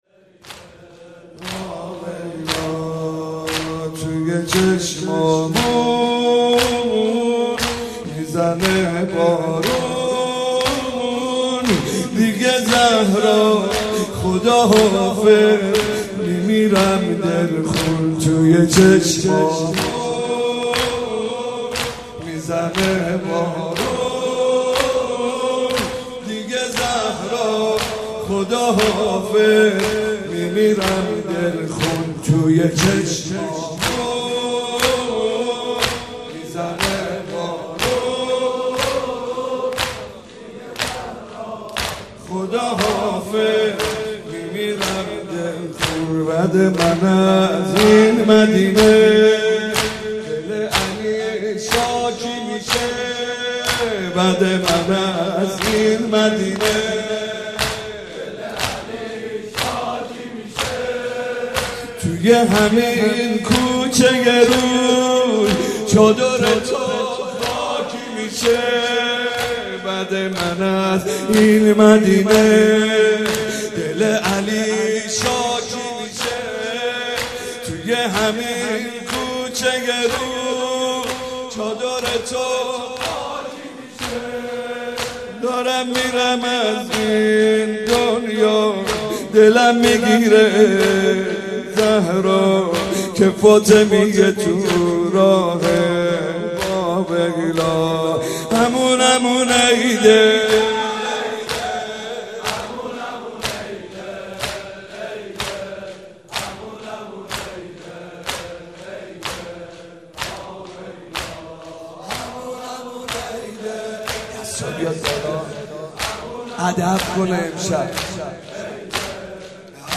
02.zamineh.mp3